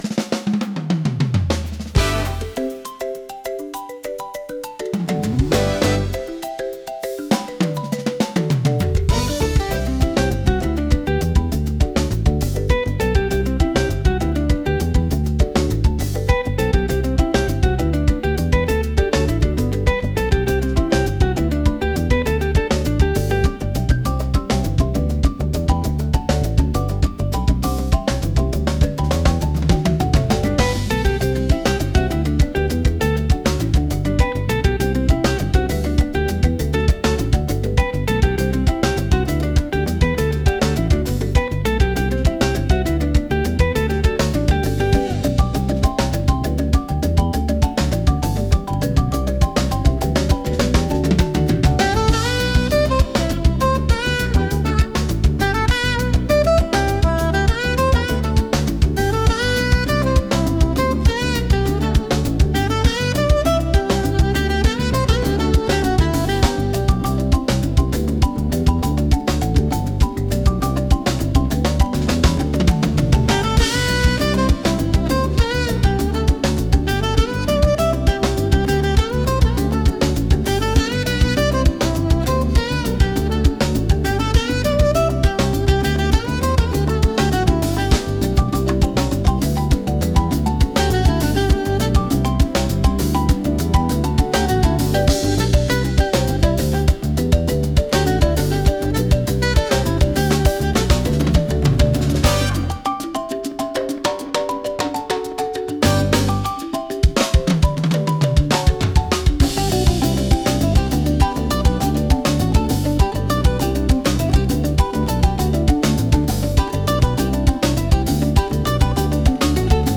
Style : Afro‑Jazz / World / Roots